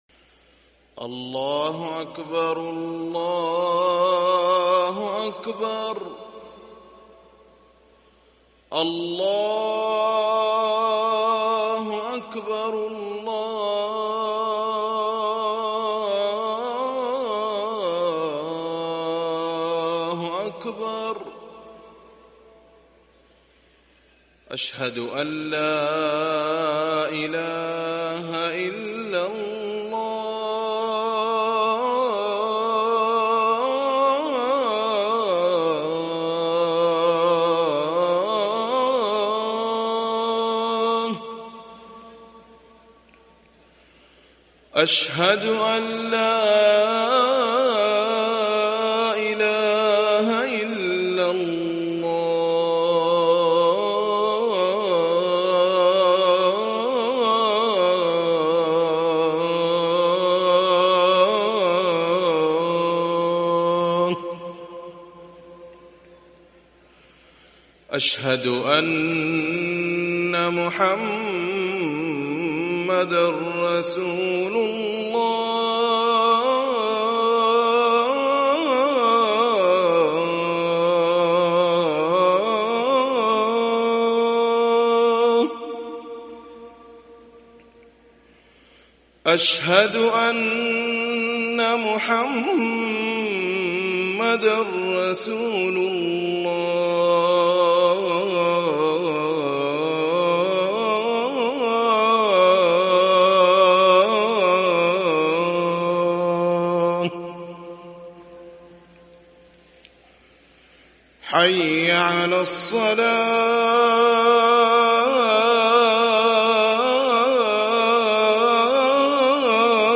أذان الفجر الأول